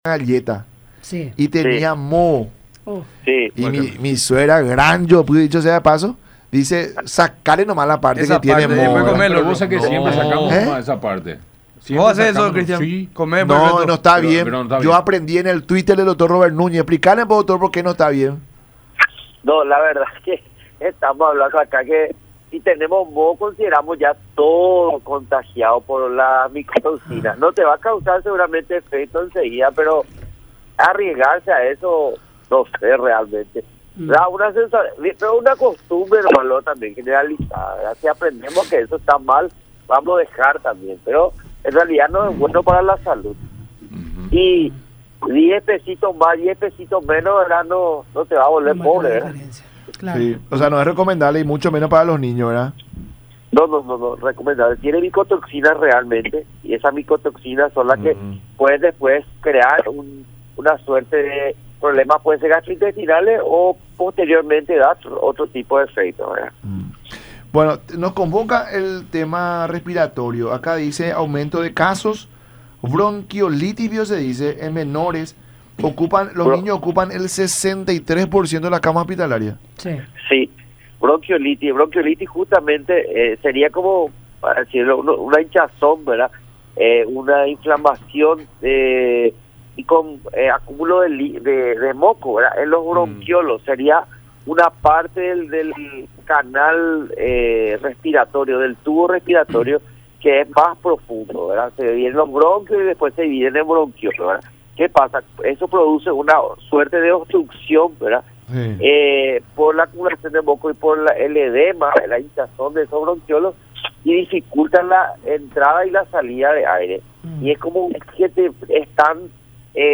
en diálogo con La Mañana De Unión por Unión TV y radio La Unión.